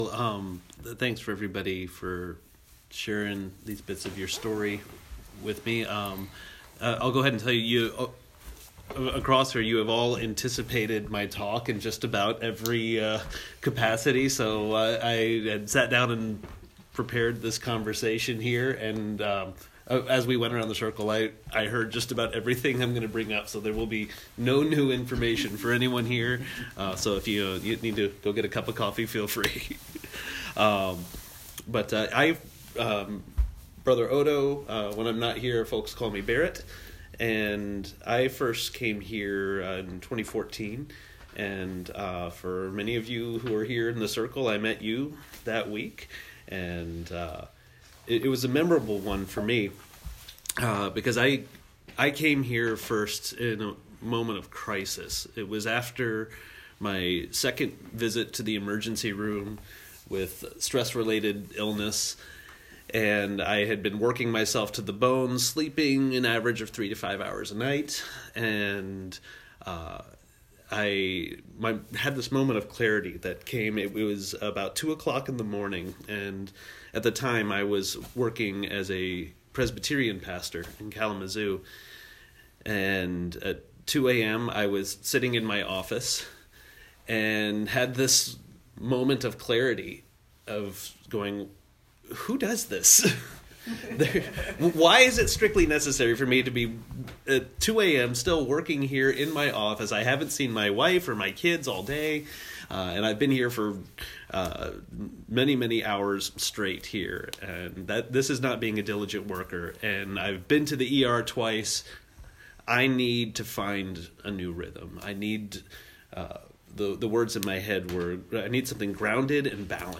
I was privileged to offer the morning session at a retreat for the Confraternity of St. Gregory’s Abbey, Three Rivers.
confraternity-day-talk.m4a